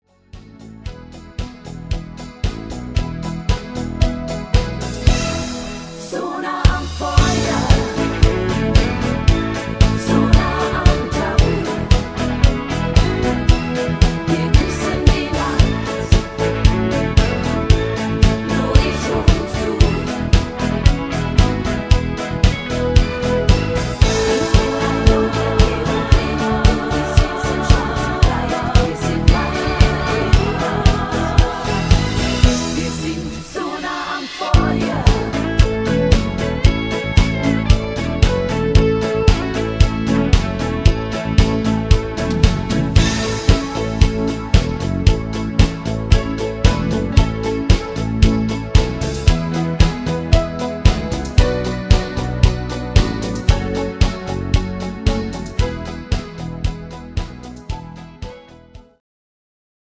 Discofox-Version